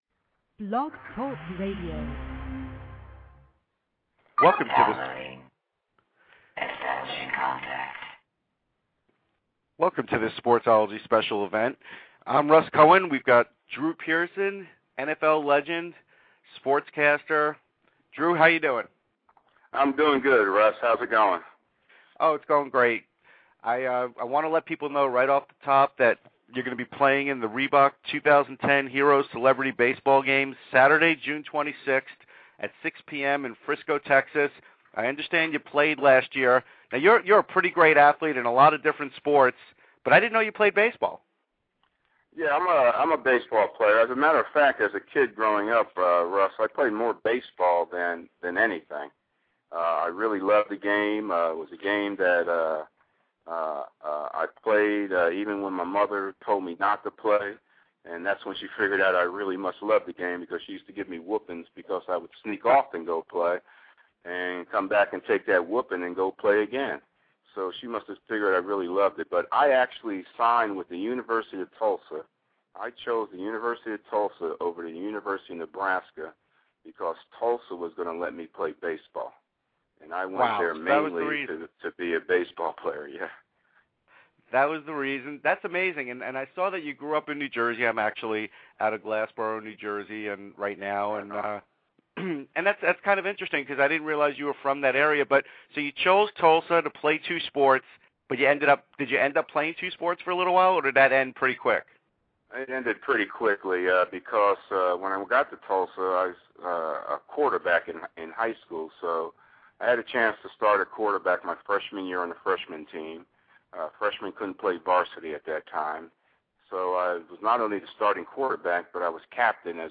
Interview with former Dallas Cowboys star Drew Pearson